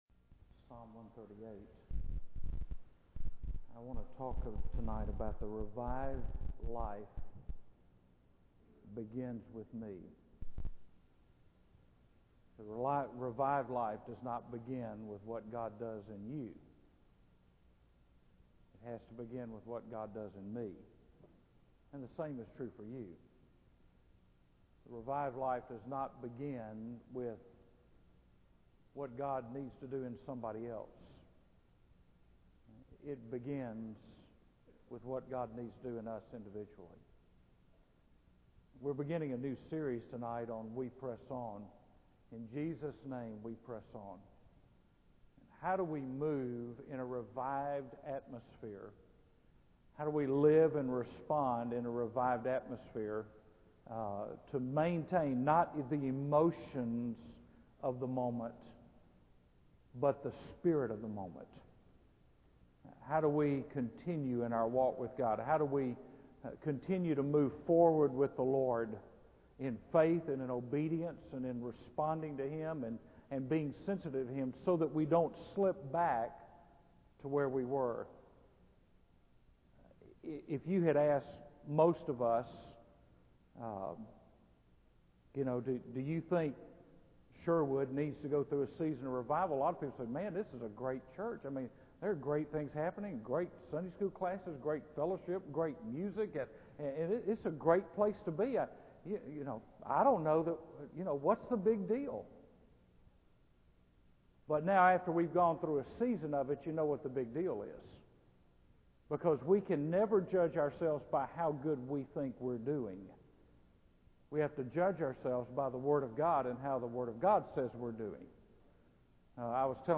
In this sermon, the speaker emphasizes the importance of pacing oneself in life. He starts by discussing the confession of desperation and failure in prayer, highlighting the need to pray consistently and not lose heart. The speaker then introduces the concept of pacing oneself using the acronym P-A-C-E, which stands for praise, attitude, confession, and expectation.